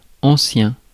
Ääntäminen
IPA : /ænˈtiːk/